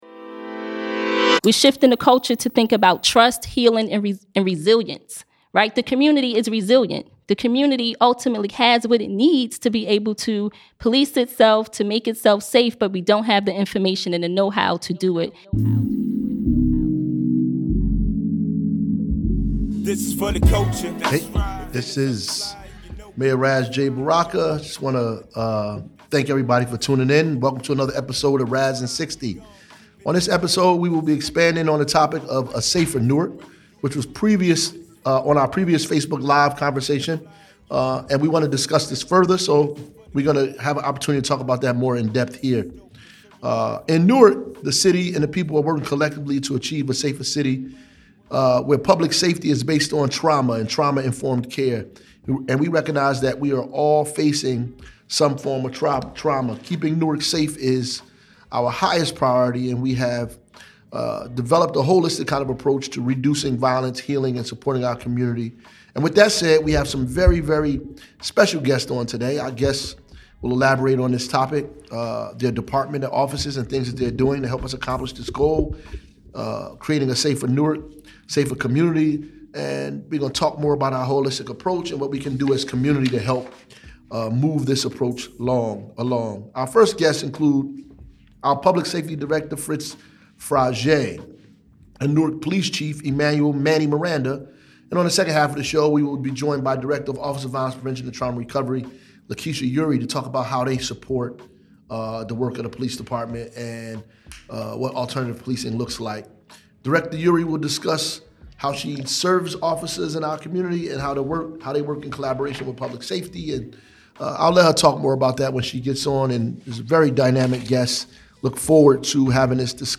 Guests featured on this episode include Director of Public Safety, Fritz Frage`and Police Chief Emanuel "Manny" Miranda, along with Director of the Office of Violence Prevention and Trauma Recovery, LaKeesha Eure.